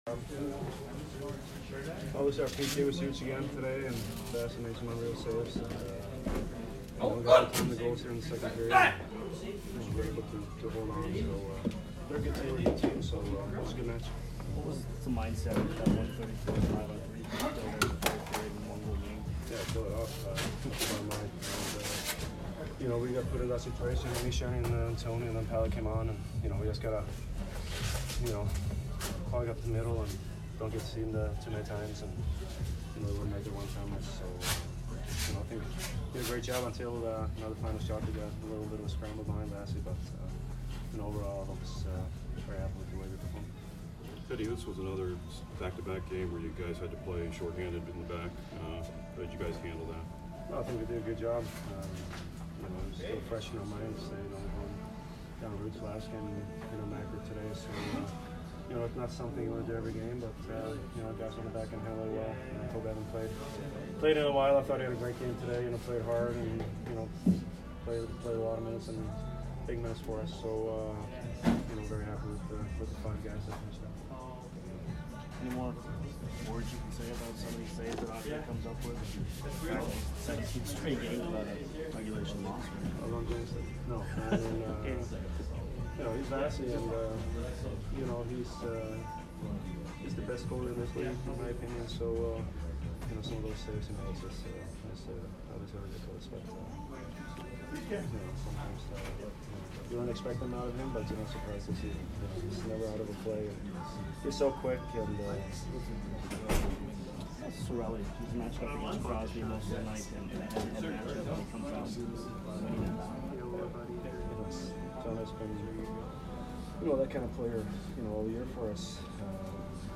Hedman post-game 2/6